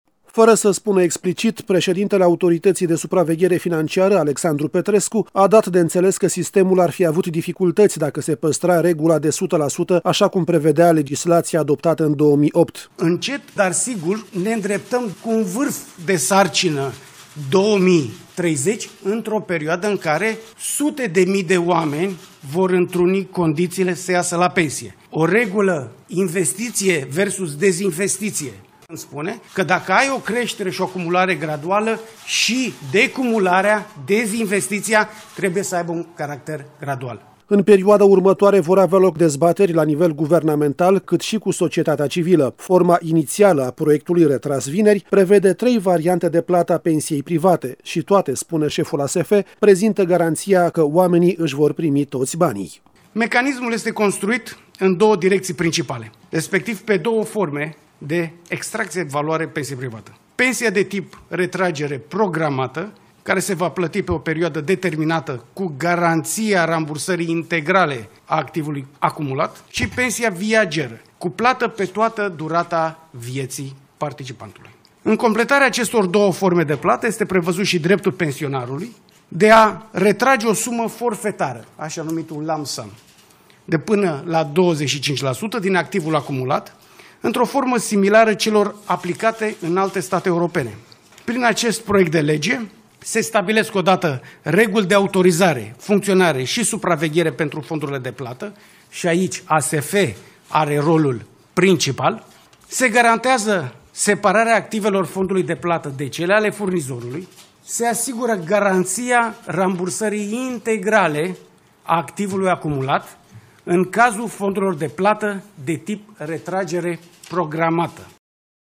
Șeful Autorității de Supraveghere Financiară, Alexandru Petrescu, cu declarații